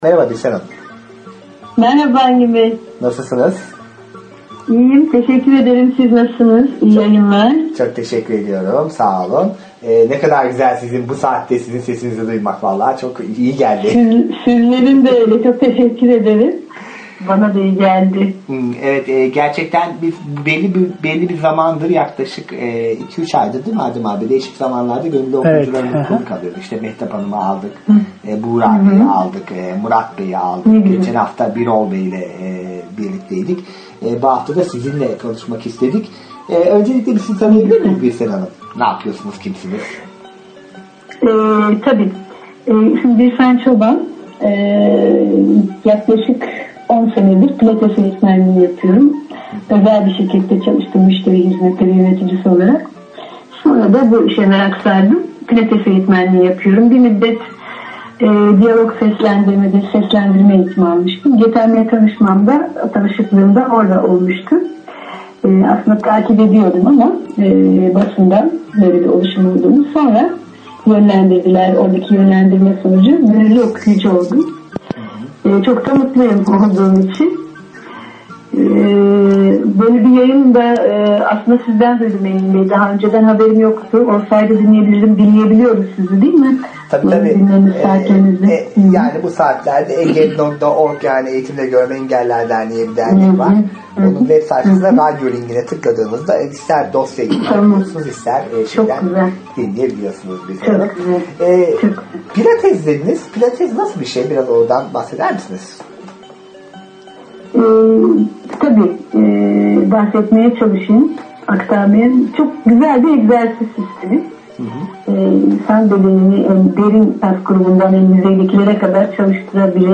Gönüllü okuyucu röportajları